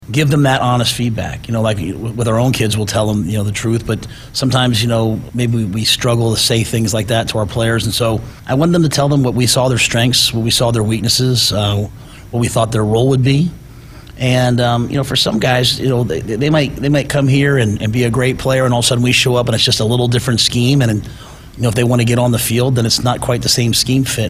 Matt Rhule, Nebraska Football Head Coach spoke on Sports Nightly on Monday night and was asked how his coaching staff handles conversations with players who are entering the transfer portal…